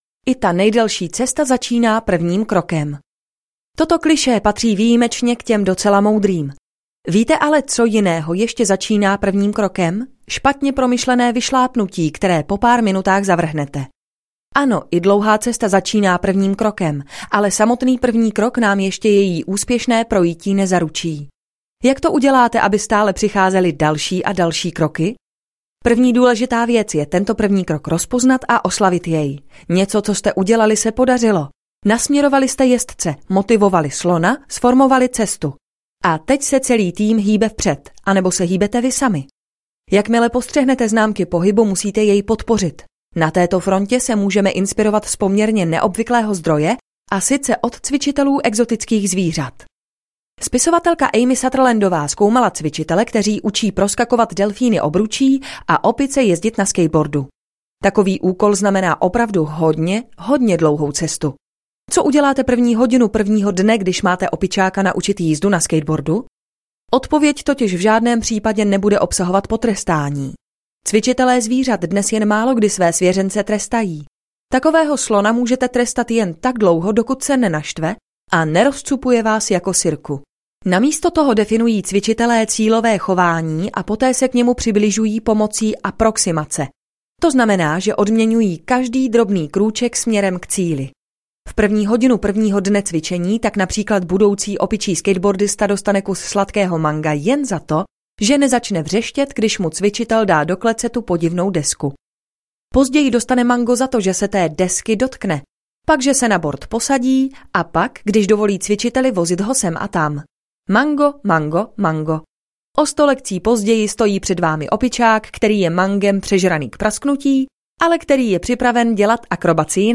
Proměna audiokniha
Ukázka z knihy